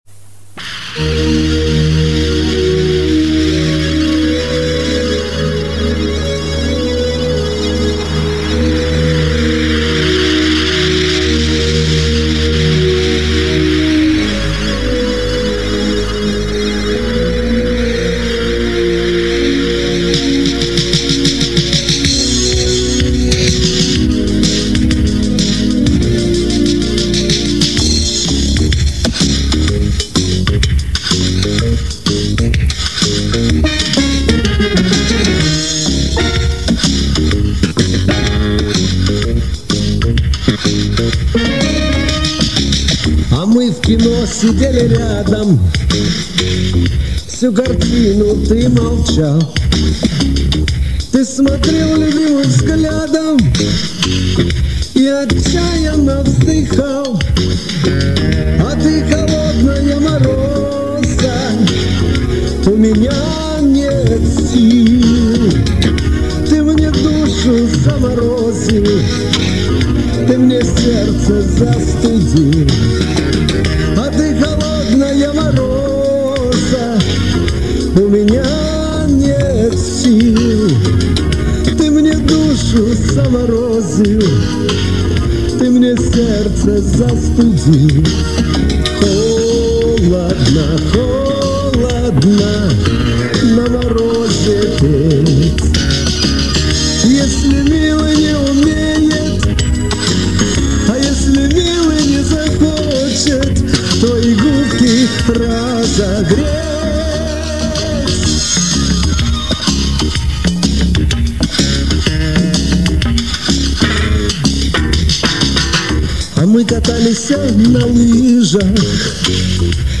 Партия ритм-гитары